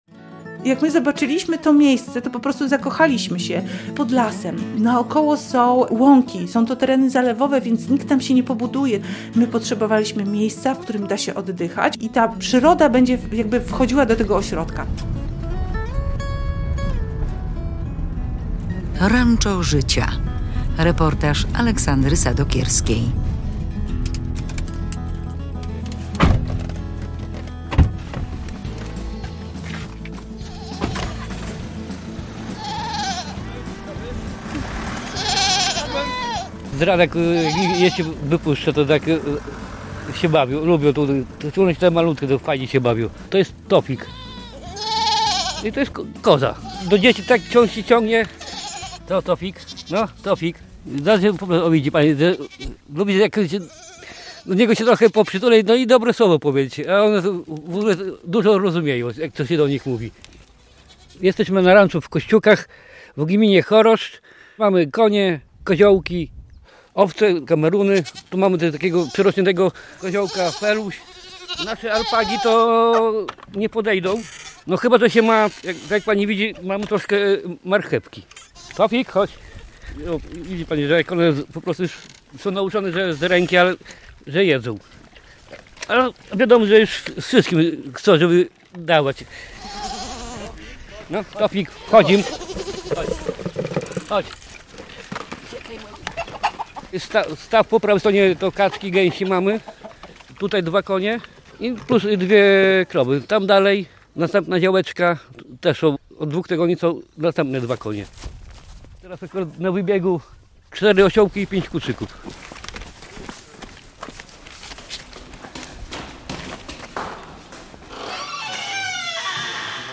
"Ranczo życia" - reportaż